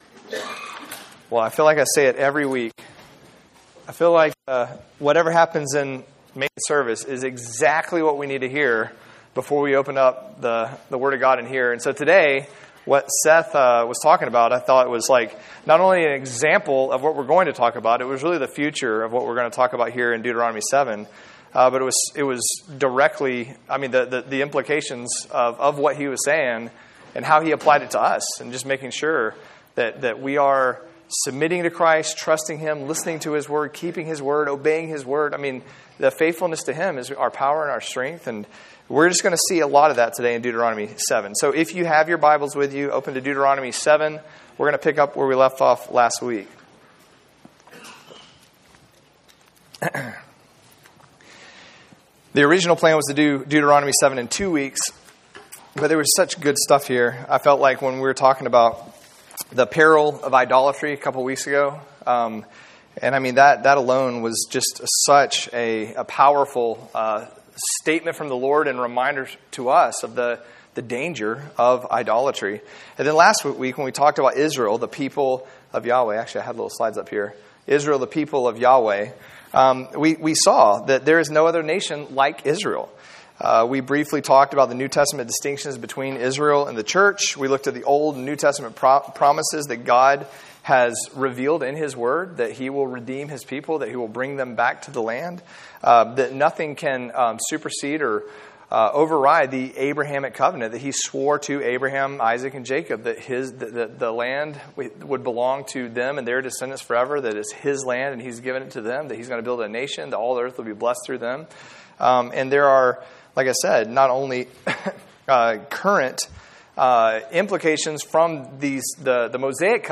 Series: Bible Studies, Deuteronomy